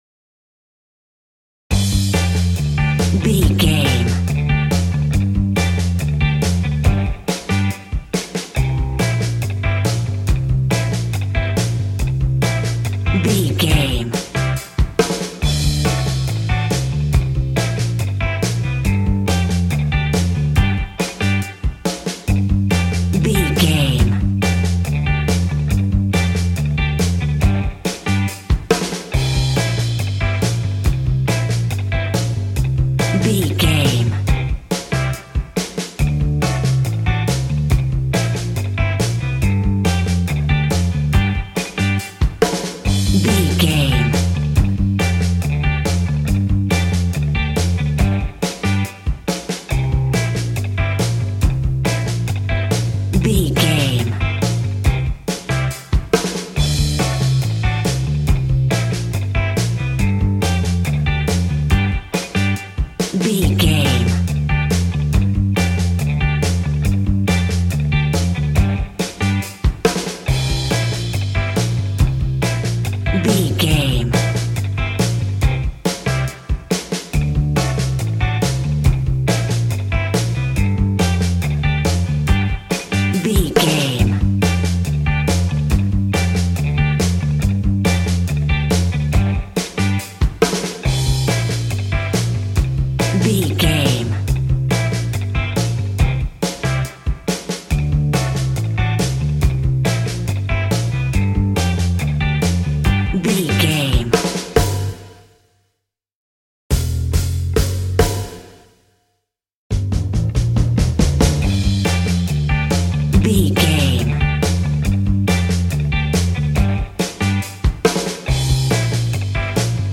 Ionian/Major
cheerful/happy
double bass
drums
piano